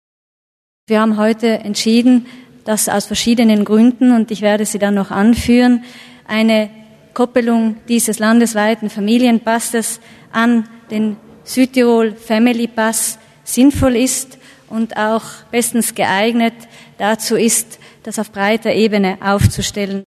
Landesrätin Deeg zu den Neuheiten beim Familienpass